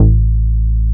R MOOG A2P.wav